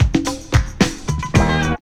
08DR.BREAK.wav